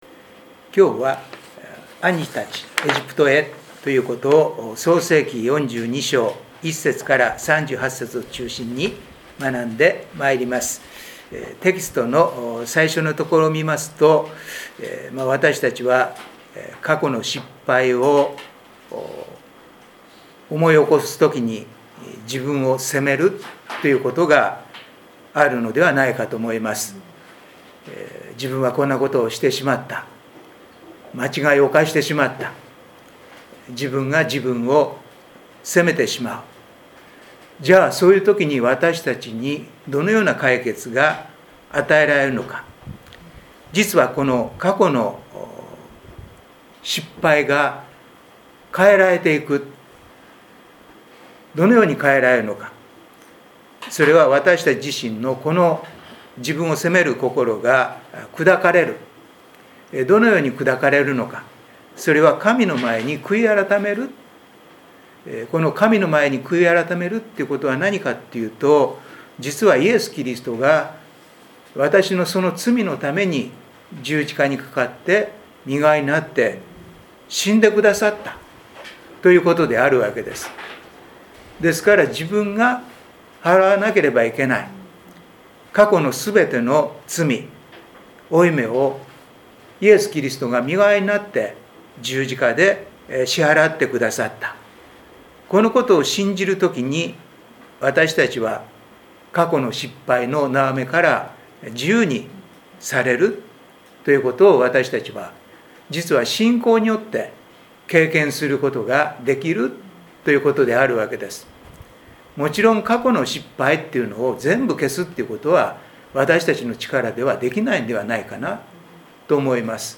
2025/7/2 聖書研究祈祷会